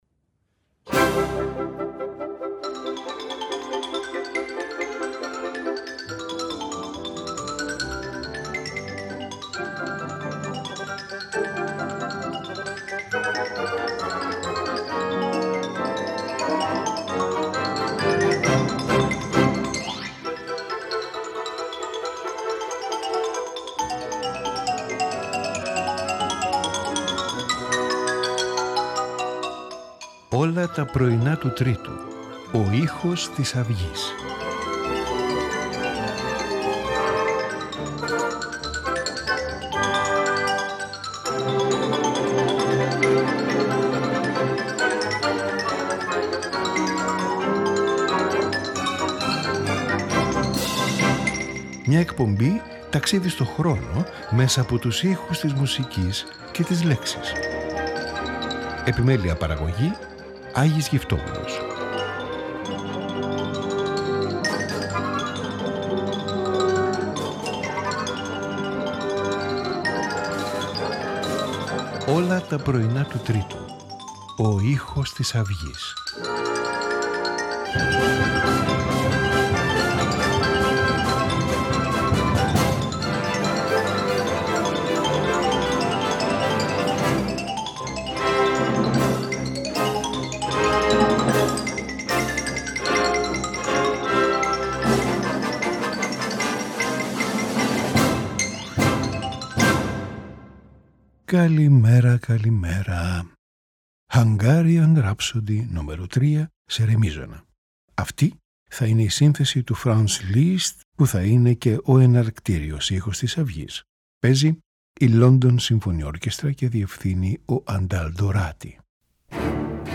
Piano Quartet No 2 in D
Six Pieces for Piano Solo